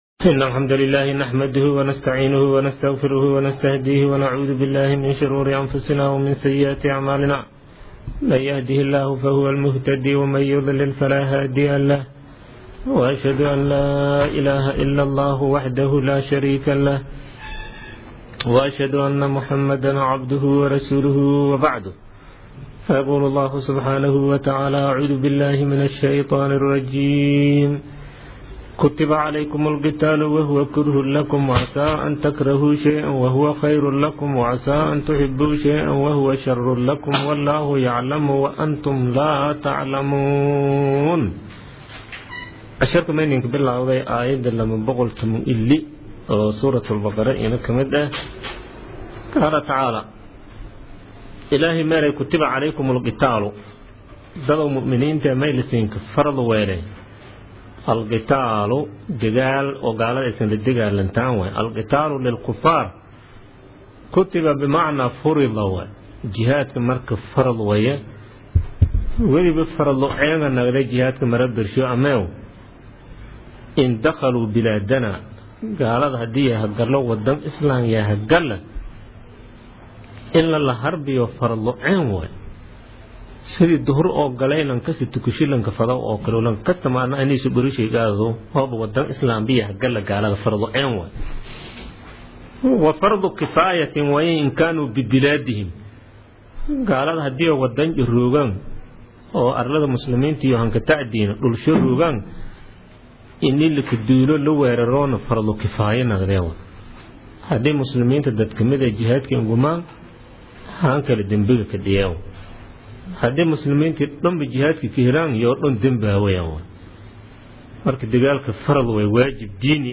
Casharka Tafsiirka Maay 27aad